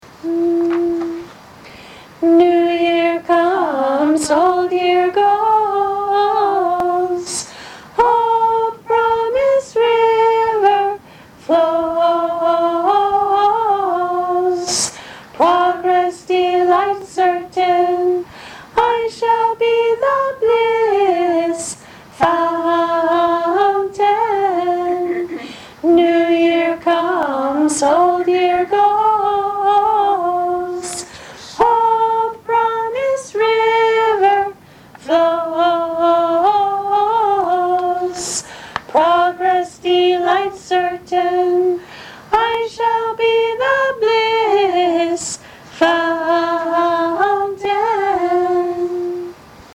The above 4 songs as a set, sung 1x each: